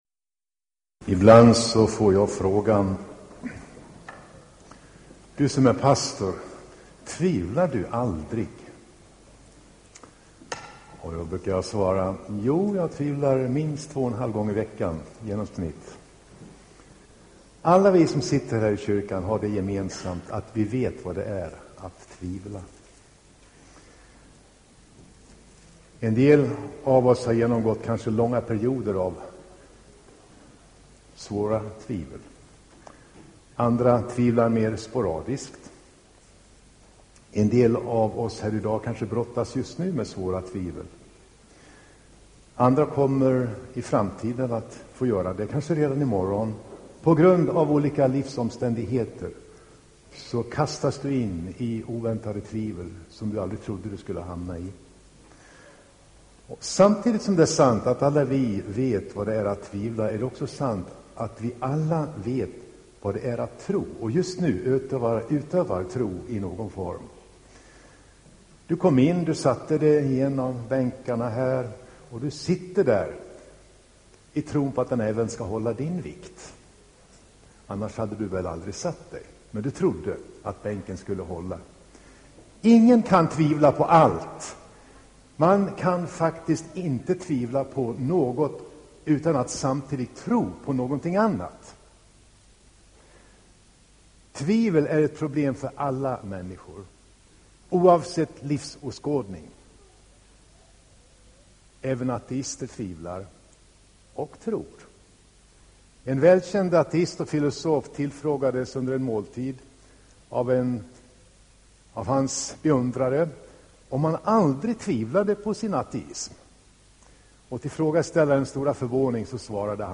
Inspelad i Tabernaklet i Göteborg 2009-02-01.